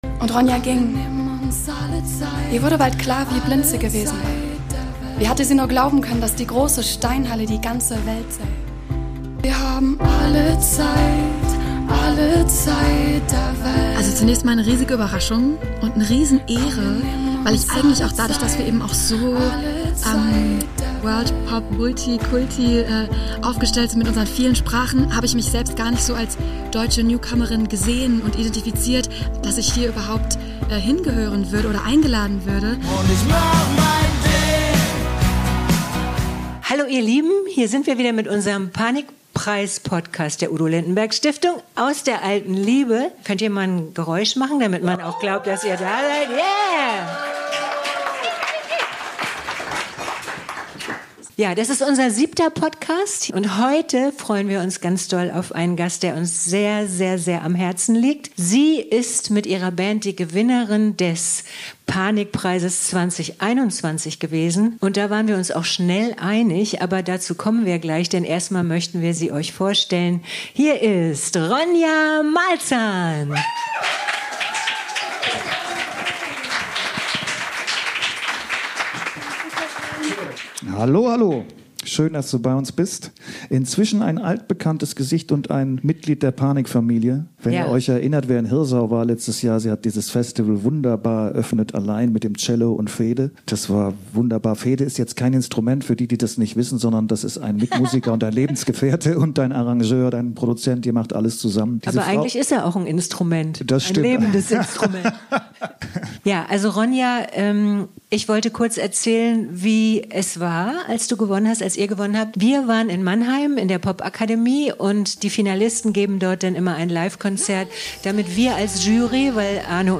live aus der Alten Liebe in Hamburg